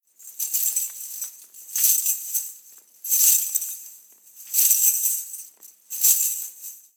Danza árabe, bailarina haciendo el movimiento de camello 01
Sonidos: Especiales
Sonidos: Música
Sonidos: Acciones humanas